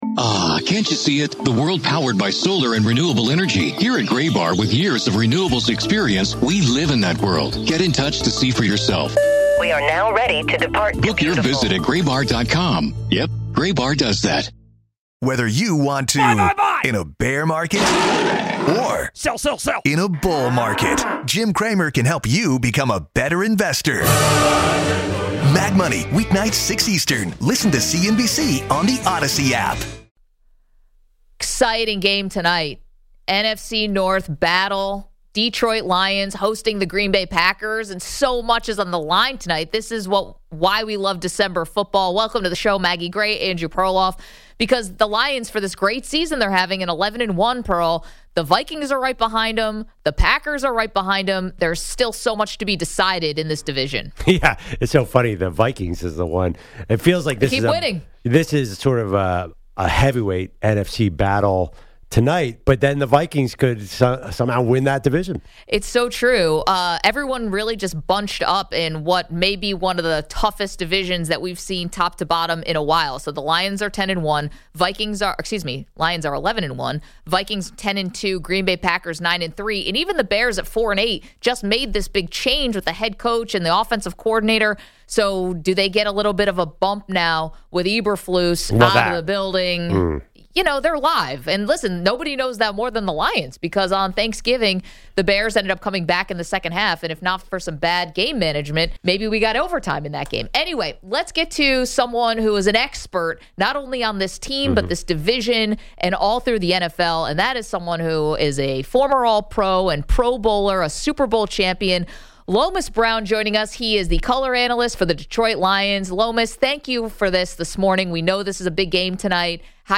Lomas Brown, Lions Color Analyst, joins the show